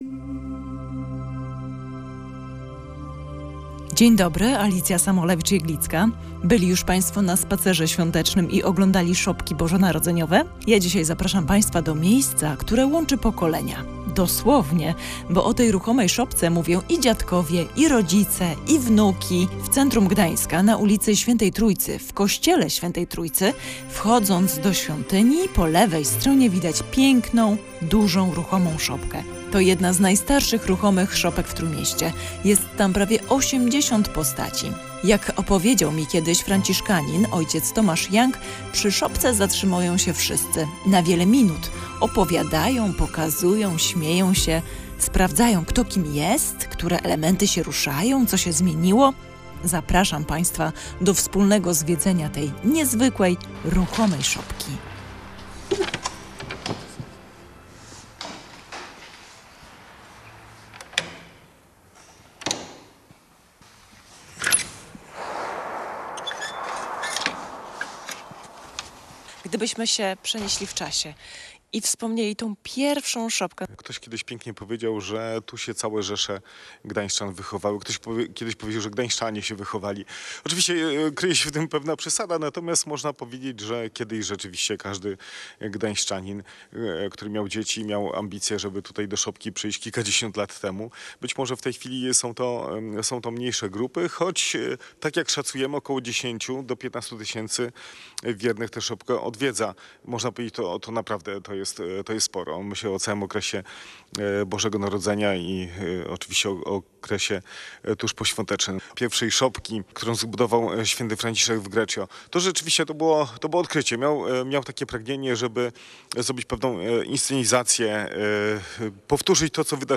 Miejsce, które łączy pokolenia. Zwiedzamy ruchomą szopkę w kościele św. Trójcy w Gdańsku